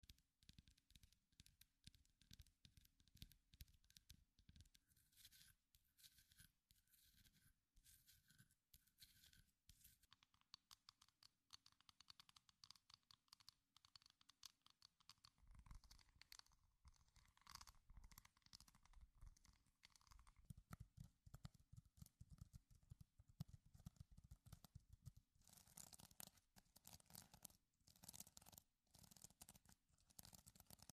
ASMR Seashell Tapping & Scratching. sound effects free download